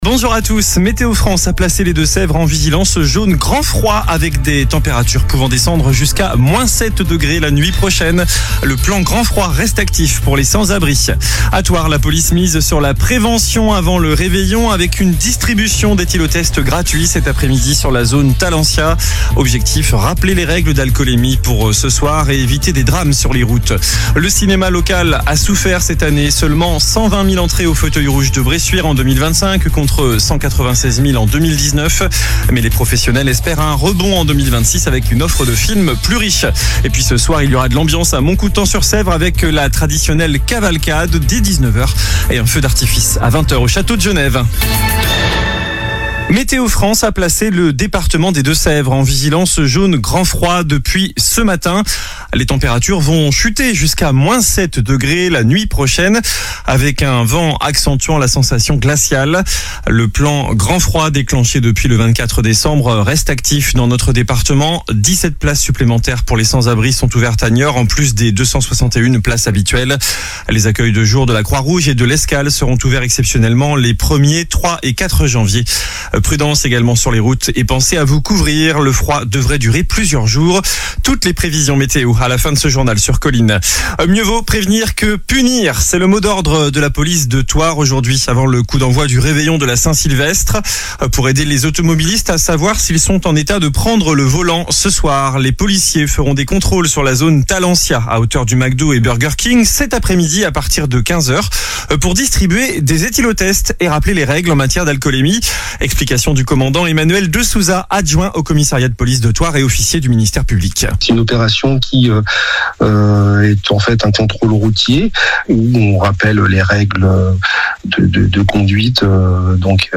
Journal du mercredi 31 décembre (midi)